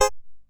TECHFX  58.wav